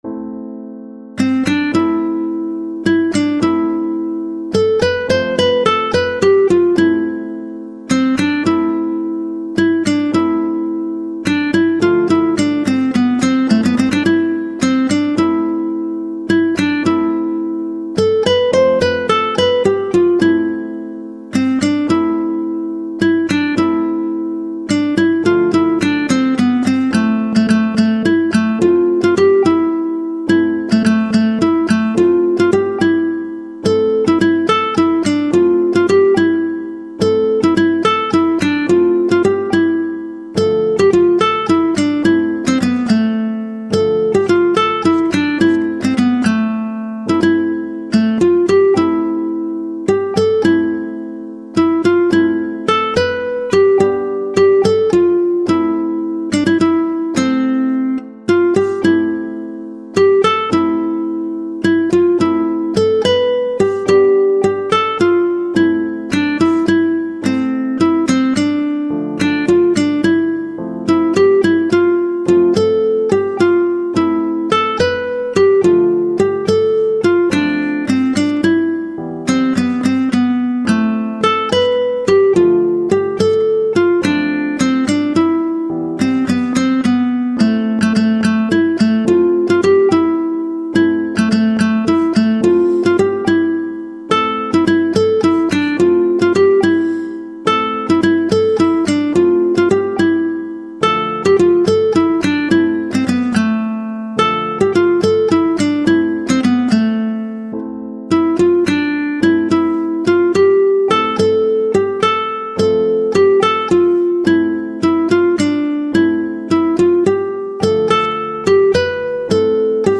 ساز : گیتار